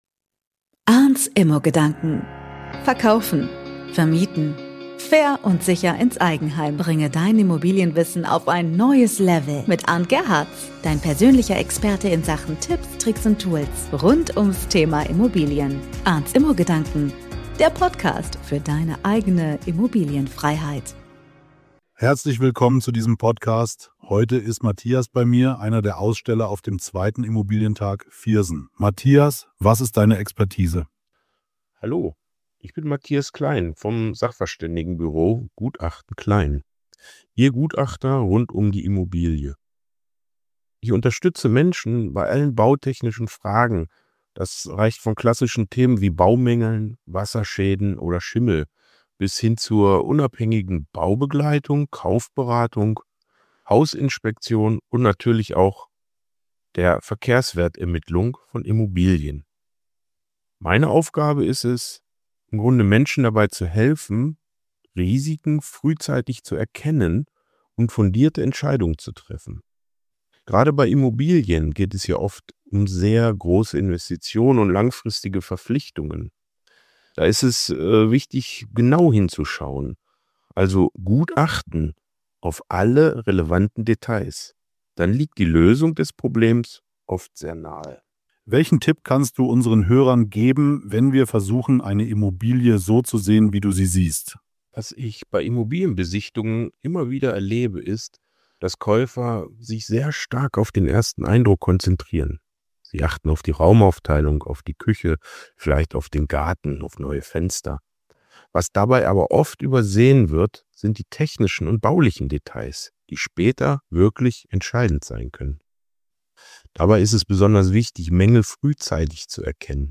Aussteller beim 2. Immobilientag Viersen